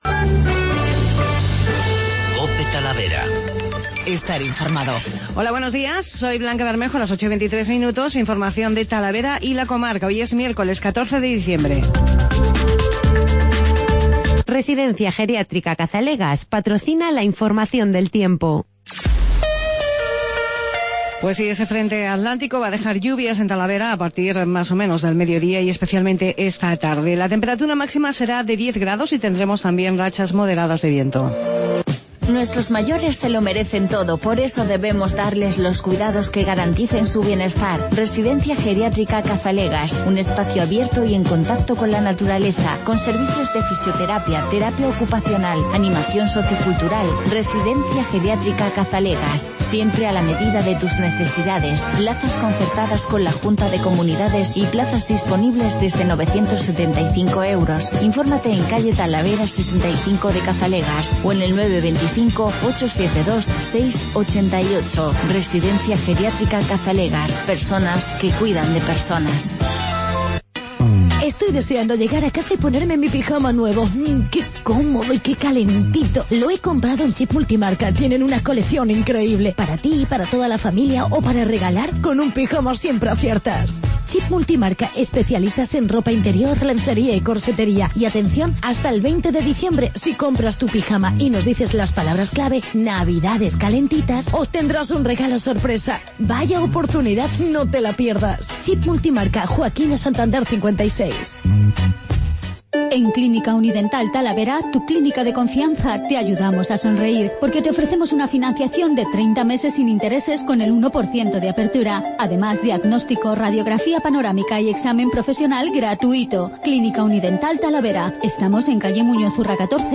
El Ministro de Fomento y el Presidente Page hablan del AVE y la plataforma logística de Talavera.